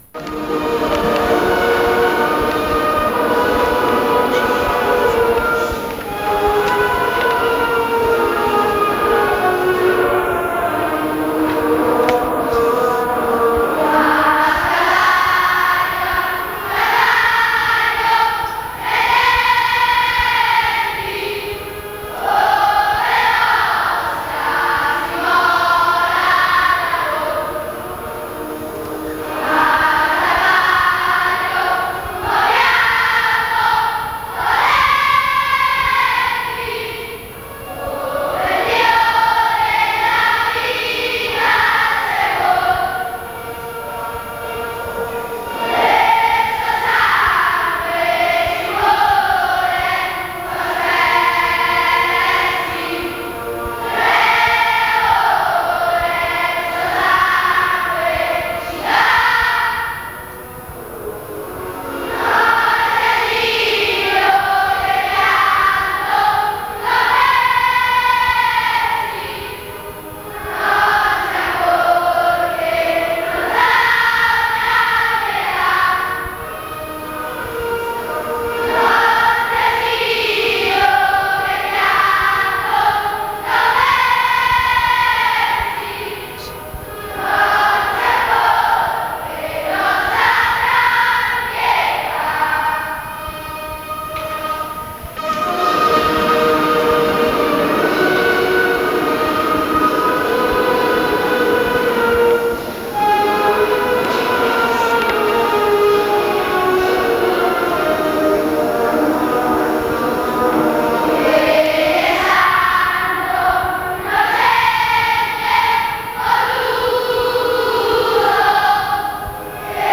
Ovviamente le registrazioni sono a livello amatoriale, per lo più registrate "live" sulle strade, se qualcuno fosse in possesso di registrazioni migliori può, se lo desidera, inviarcele in modo che esse siano messe a disposizione di tutti.